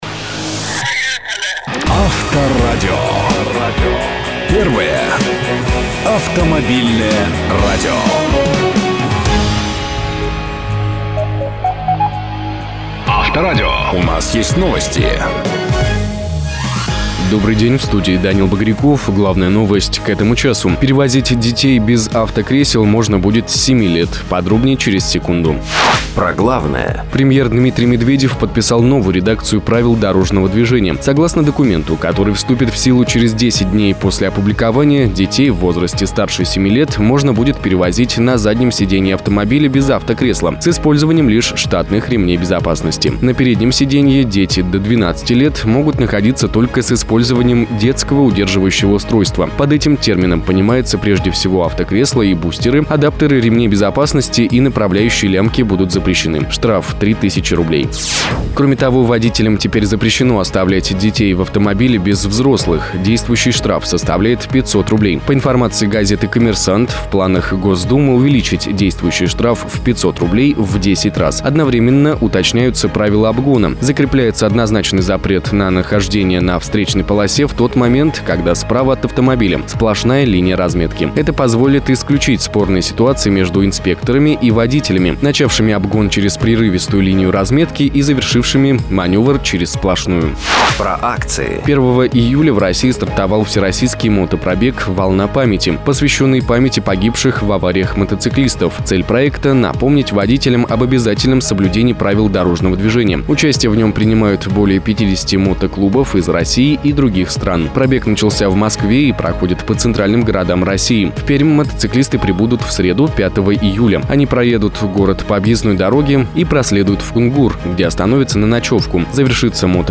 Авторадио НОВОСТИ.mp3